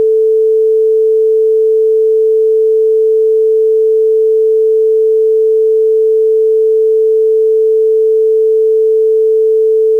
81- LA 440 Hz (10s -..> 2025-08-25 16:43  938K